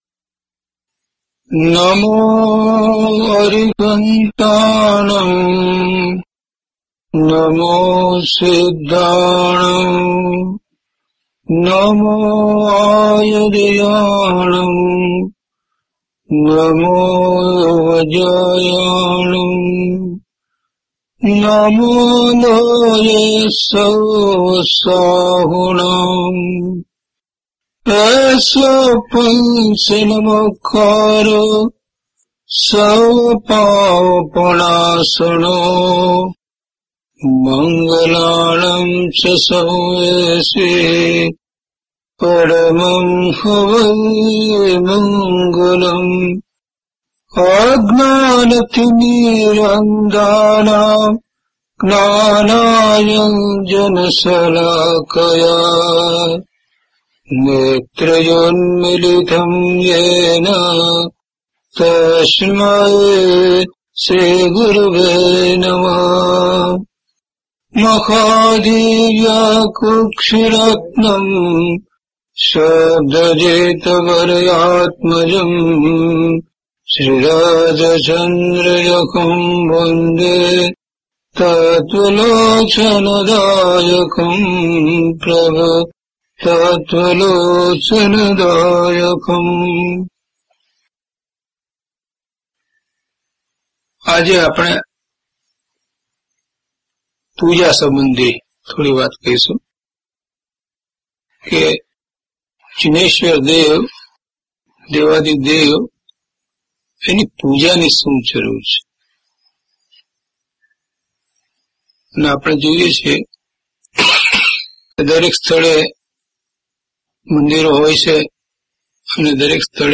DHP040 Puja and Samayik - Pravachan.mp3